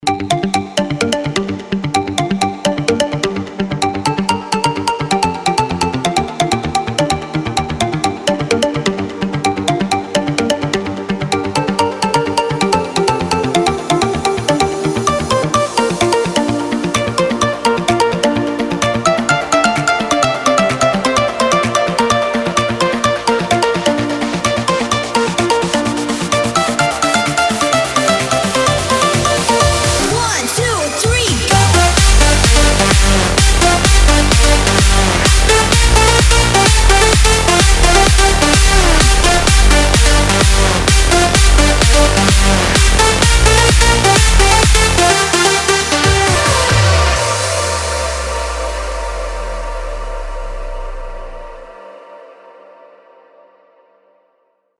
• Качество: 320, Stereo
мощные
мелодичные
нарастающие
Big Room
Мелодия по нарастающей